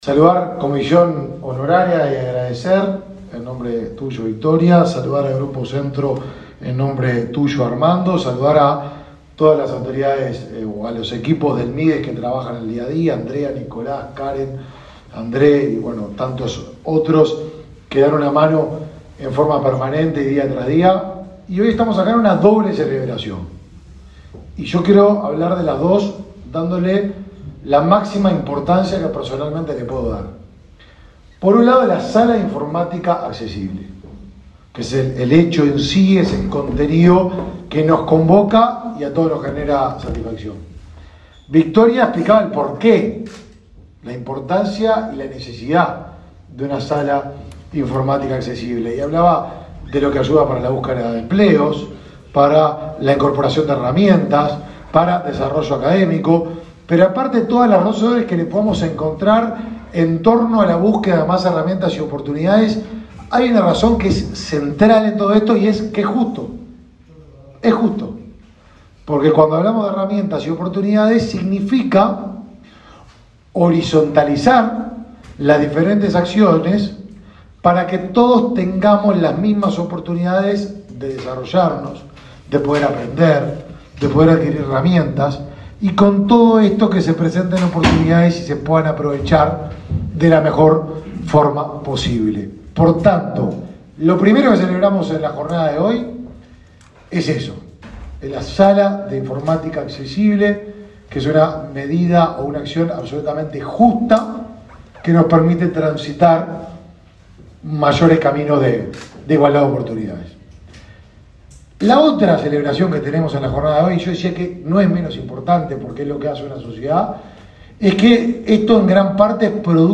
Palabras del ministro de Desarrollo Social, Martín Lema
Este jueves 17 en Montevideo, el ministro de Desarrollo Social, Martín Lema, participó en la inauguración de la sala informática accesible de Comisión